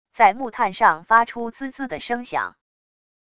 在木炭上发出滋滋的声响wav音频